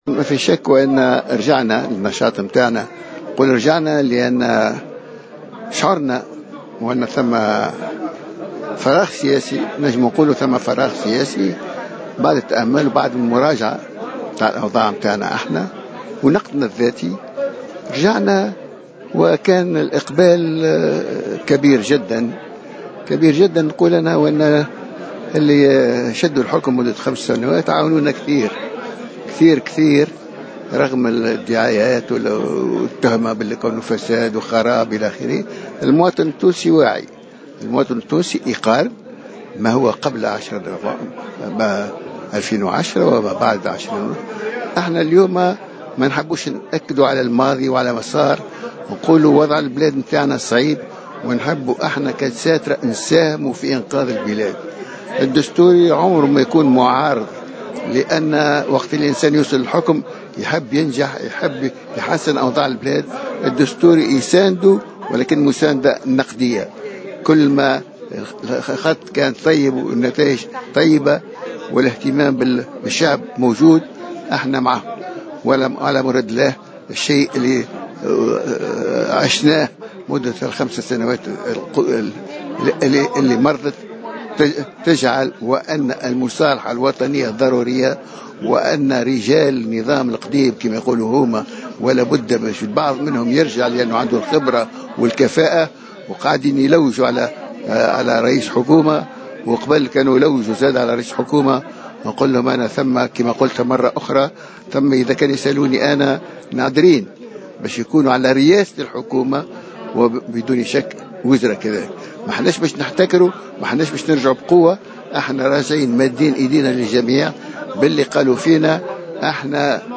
قال رئيس الحركة الدستورية اليوم السبت على هامش اجتماع للحركة في سوسة، إنه مستعد لمساعدة حكومة الوحدة الوطنية في اختيار كفاءات من النظام السابق.